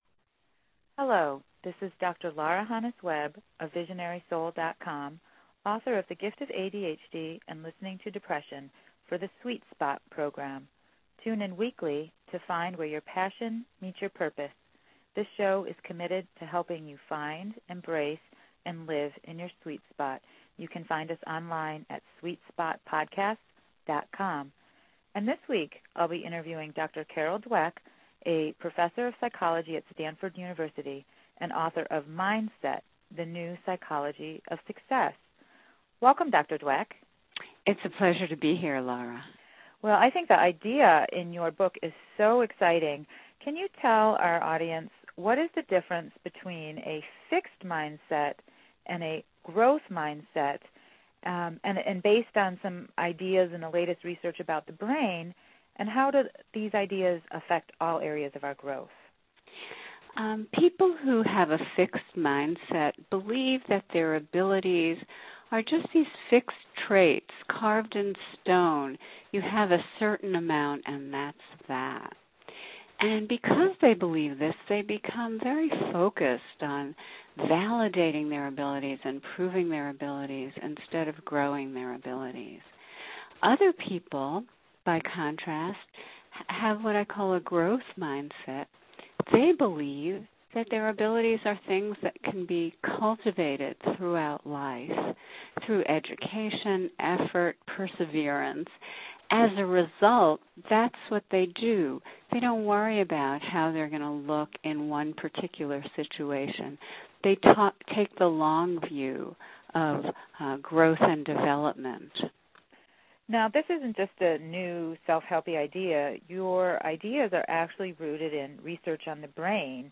This interview with Dr. Carol Dweck reminds us that people Get Smart when they are praised for their effort and not their ability. When people are praised for effort they become higher achievers and more motivated than those praised for ability.